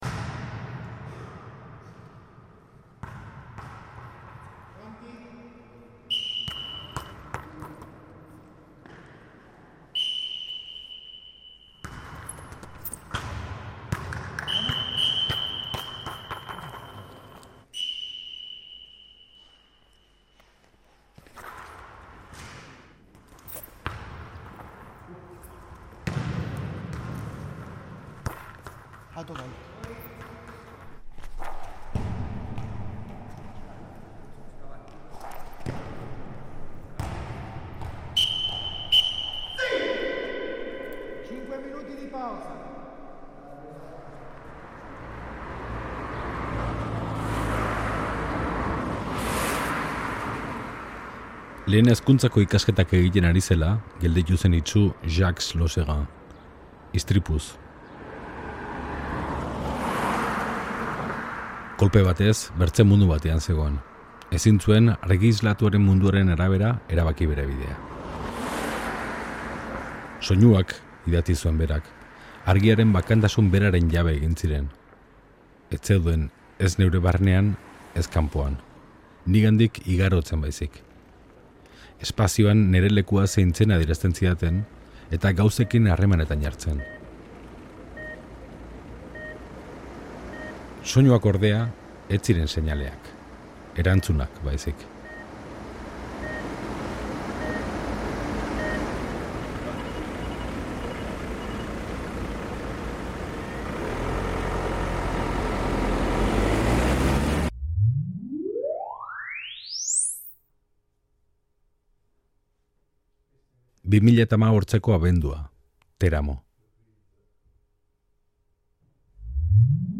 Audioa: Mundua belarriz, soinuz, ikusten dutenei buruz hilabete hontako soinu-pieza.